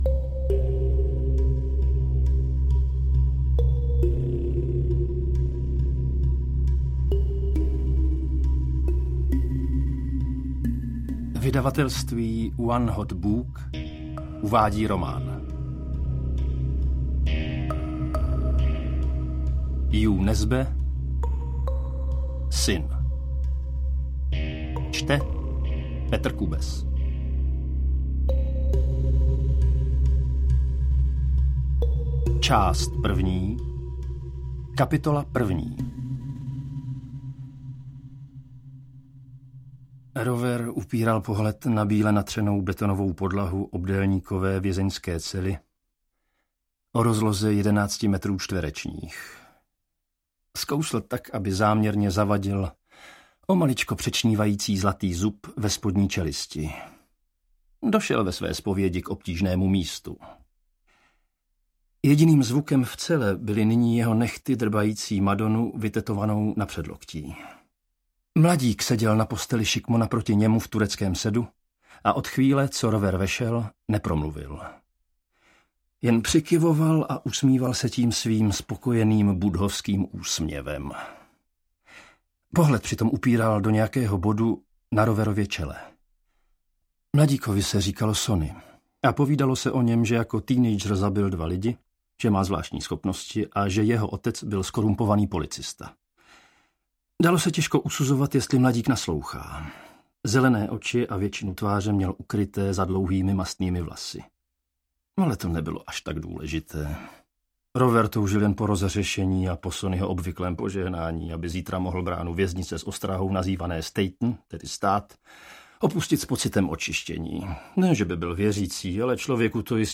Syn audiokniha
Ukázka z knihy